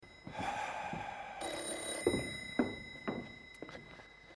Telefoon met zucht
Category: Television   Right: Personal